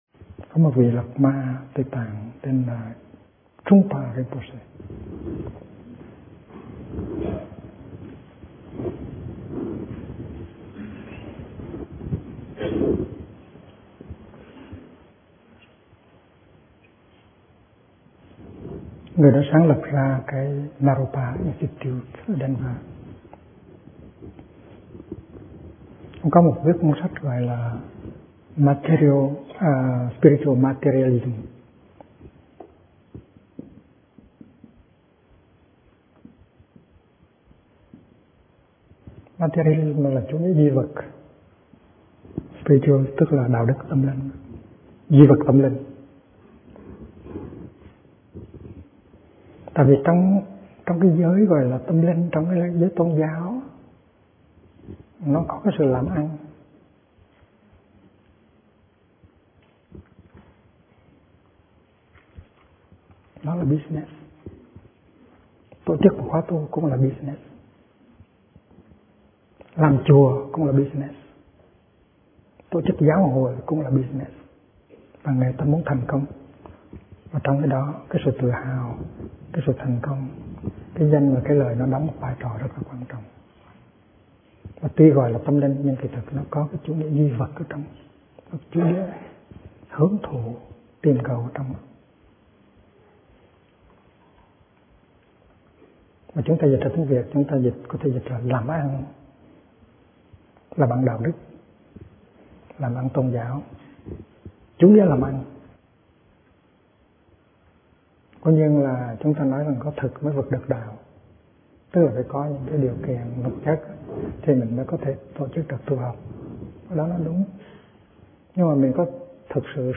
Kinh Giảng Trở Về Thực Tại - Thích Nhất Hạnh